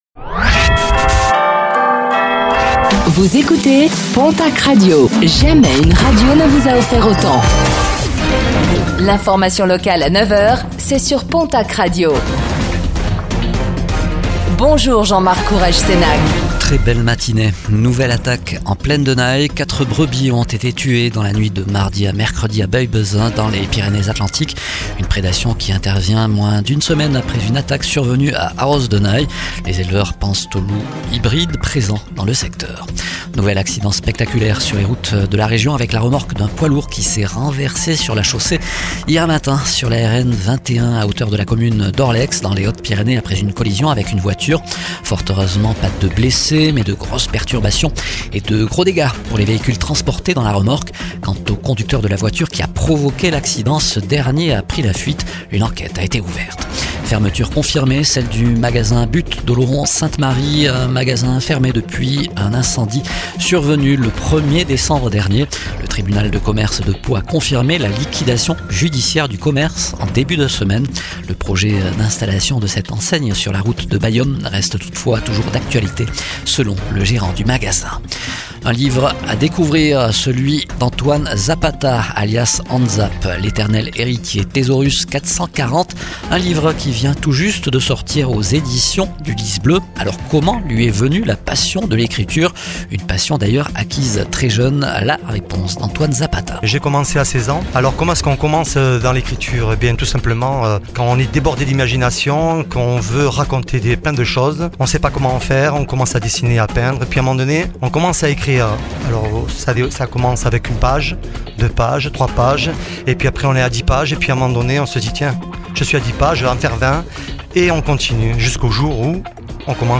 09:05 Écouter le podcast Télécharger le podcast Réécoutez le flash d'information locale de ce jeudi 19 mars 2026